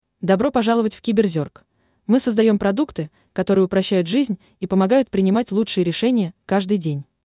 Для тестинга мы взяли бюджетный вариант – женский голос Ксения. Нейтральная подача, скорость – 4.
По звучанию голос воспринимается несколько механическим. Он больше походит на речь неуверенной молодой девушки, чем на озвучку опытного диктора.